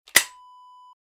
Shotgun action close sound effect .wav #2
Description: The sound of closing the break-action of a shotgun
Properties: 48.000 kHz 16-bit Stereo
A beep sound is embedded in the audio preview file but it is not present in the high resolution downloadable wav file.
shotgun-action-close-preview-2.mp3